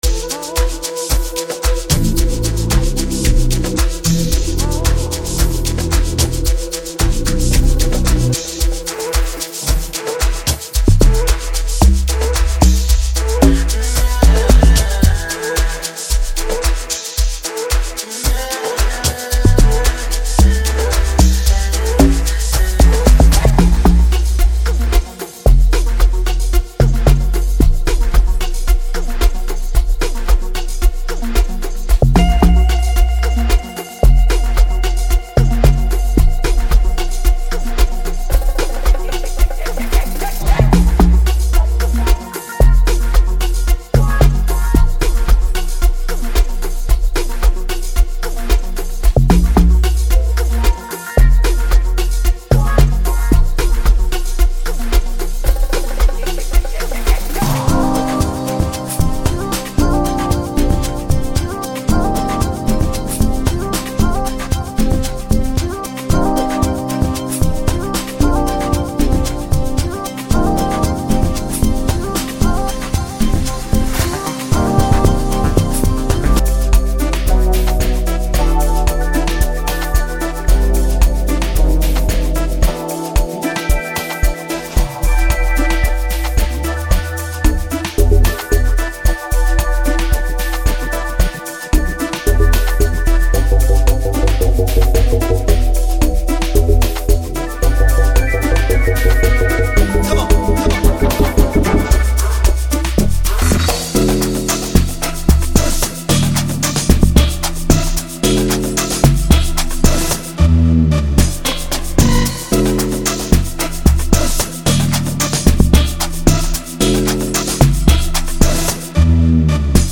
Amapiano Beats